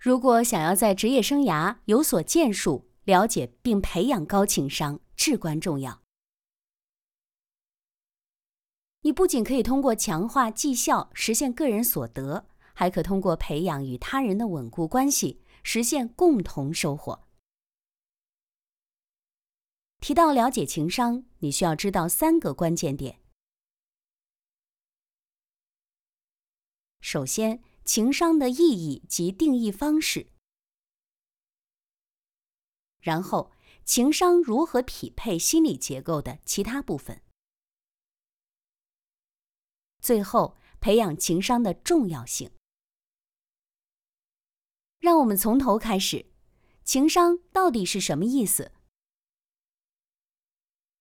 Chinese_Female_041VoiceArtist_2Hours_High_Quality_Voice_Dataset